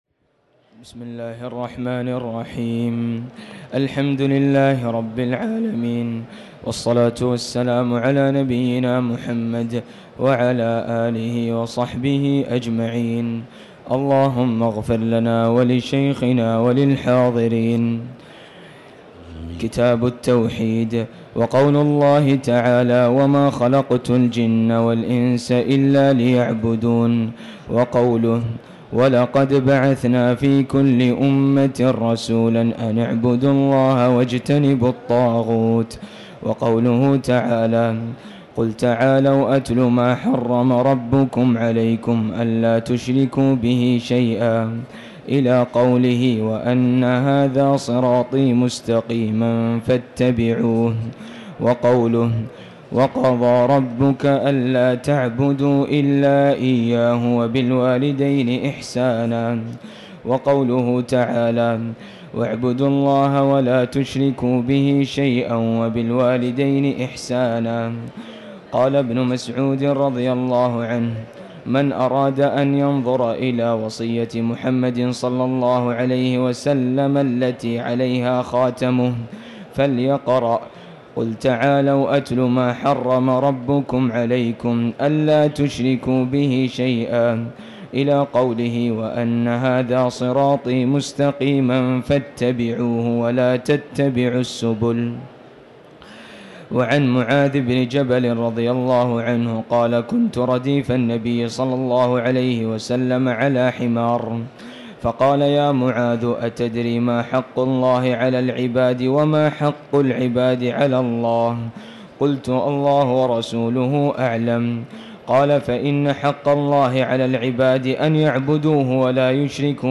تاريخ النشر ٢ رمضان ١٤٤٠ هـ المكان: المسجد الحرام الشيخ